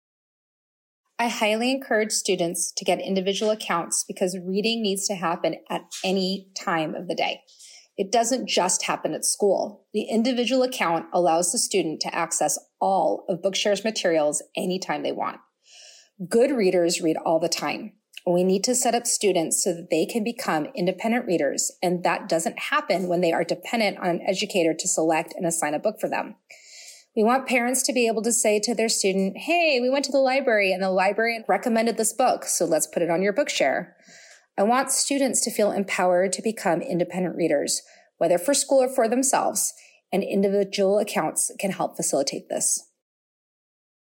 Assistive Technology Specialist